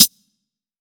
Closed Hats
TC3Hat16.wav